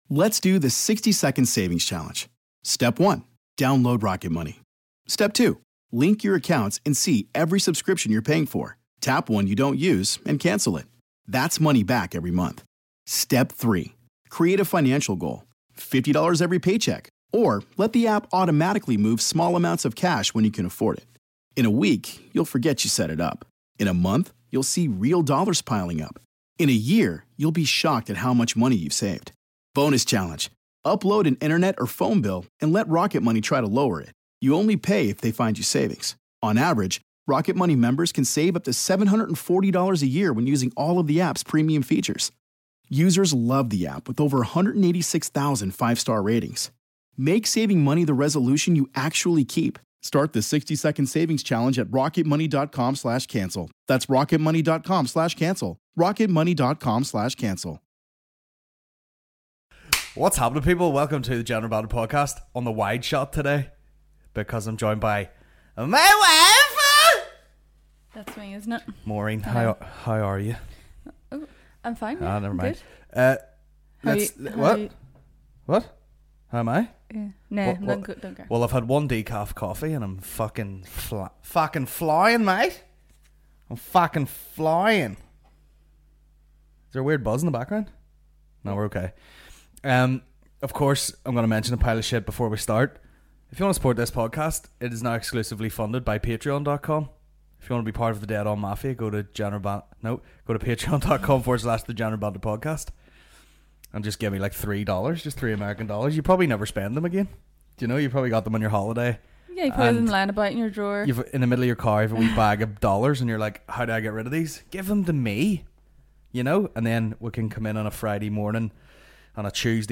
Comedy podcast